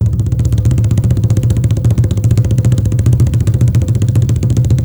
-ZULU ROLL-L.wav